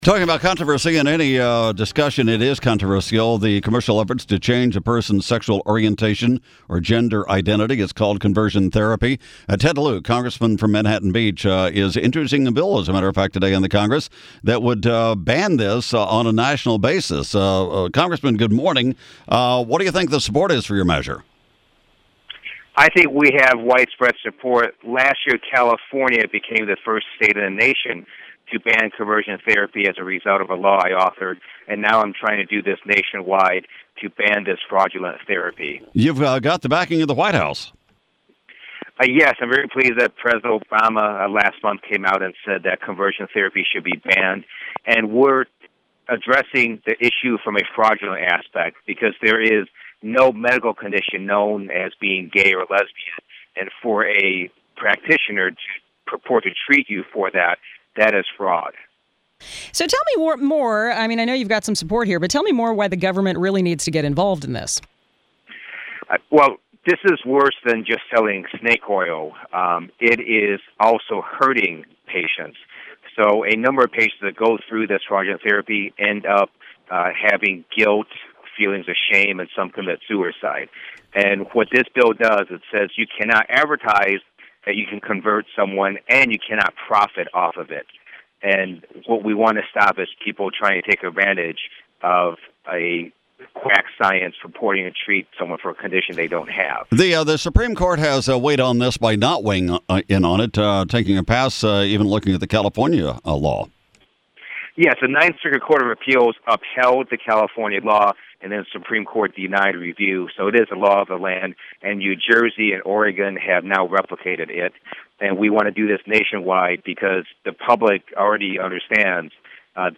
Today, Congressman Lieu discussed the Therapeutic Fraud Prevention Act, the first federal bill to stop conversion therapy, on KNX 1070 News Radio in Los Angeles.
Rep Ted Lieu KNX 5-19-15.mp3